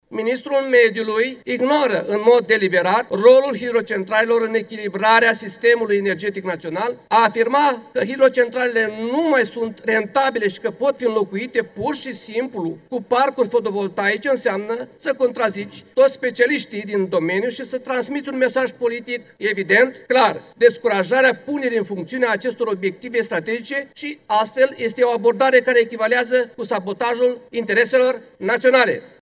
Moțiunea simplă împotriva ministrului Mediului a fost dezbătută în Camera Deputaților.
Silviu Urlui, deputat AUR: „Este o abordare care echivalează cu sabotajul intereselor naționale”